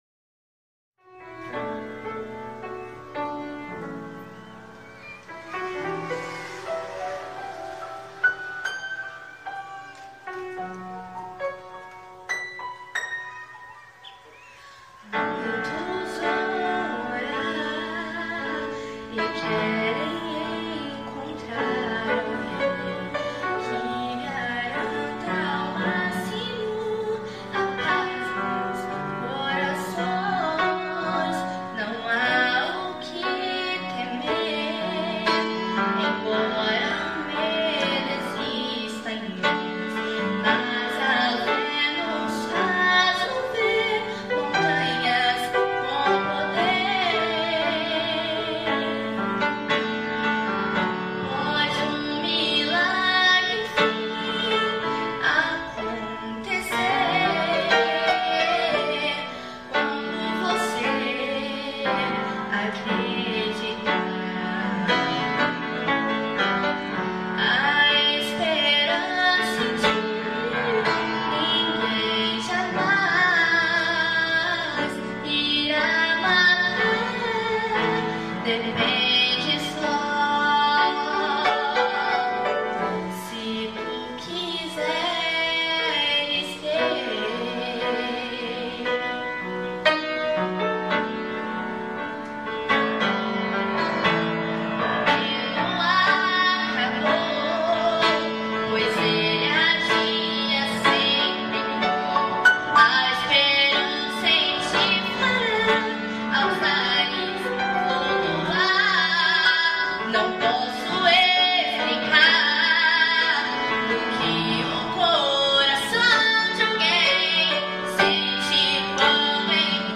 Solista